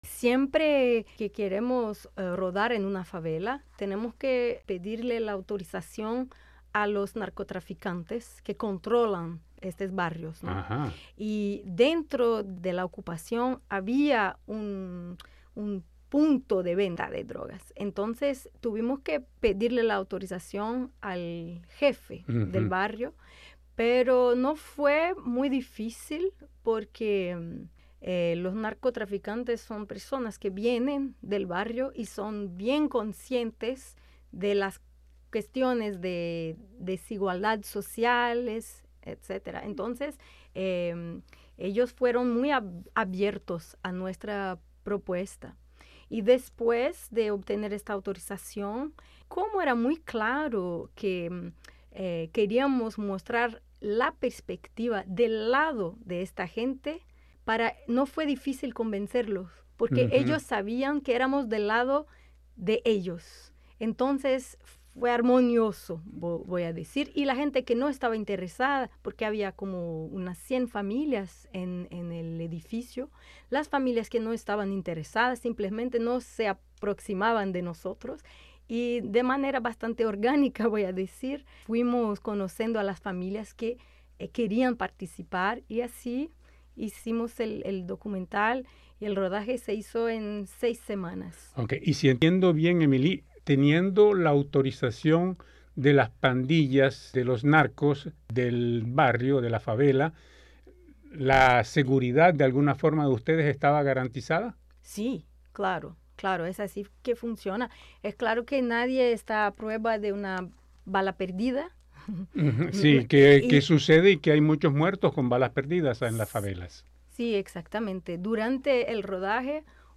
Al momento de hacer esta entrevista ya la película estaba siendo presentada en salas.